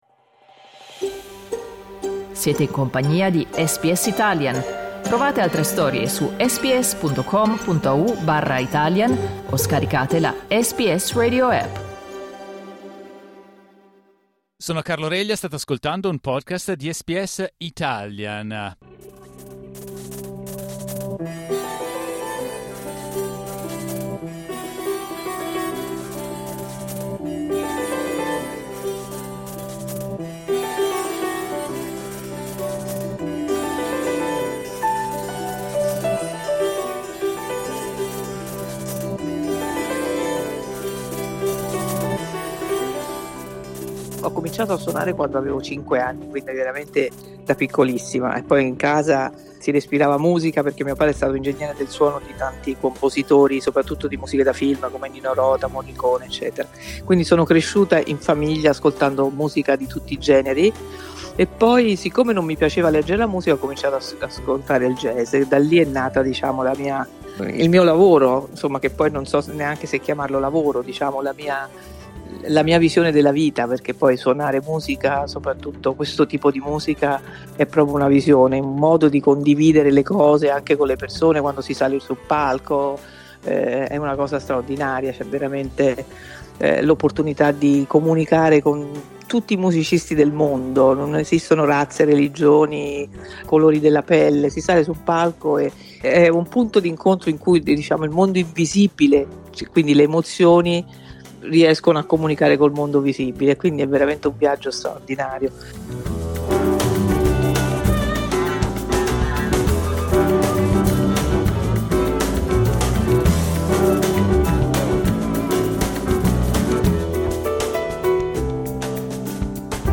Ai microfoni di SBS Italian, Rita racconta la sua carriera, che nasce con la passione per il piano da piccolina, prima di svilupparsi in collaborazioni con i grandi miti del jazz mondiale.